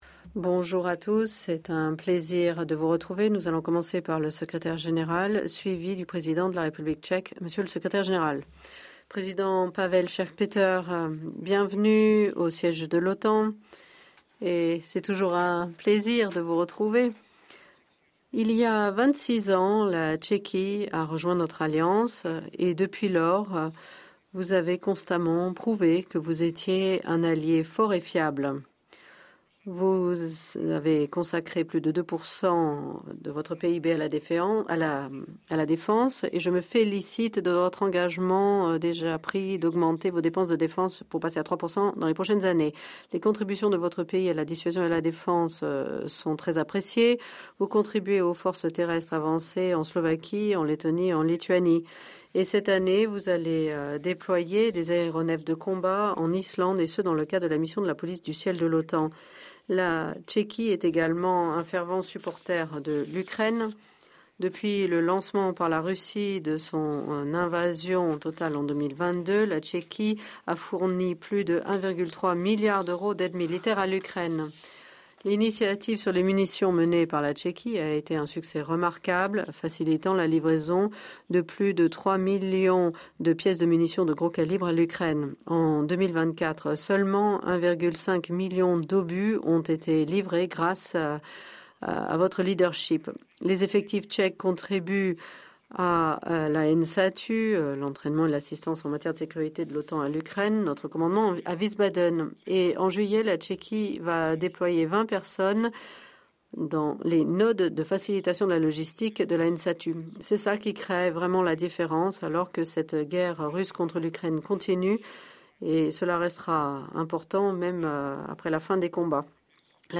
Joint press conference with NATO Secretary General Mark Rutte and the President of Czechia, Petr Pavel